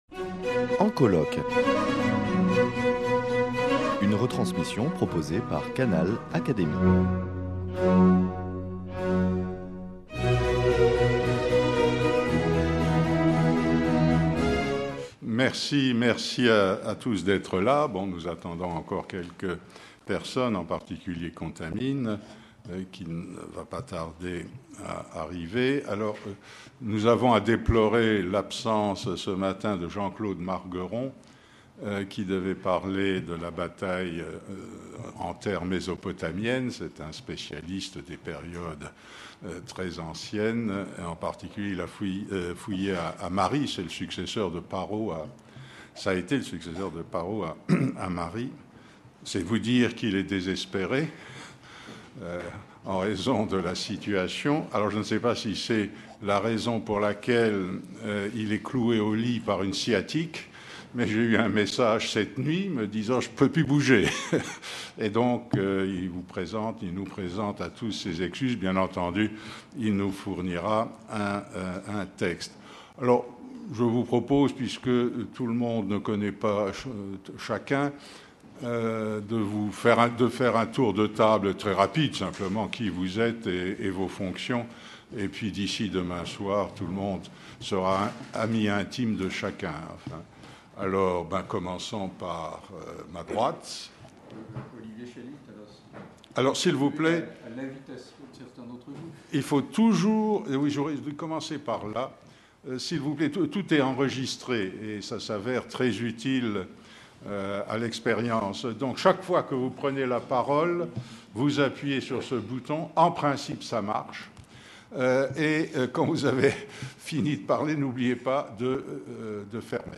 Retransmission du colloque international « La guerre et le droit » - Partie 1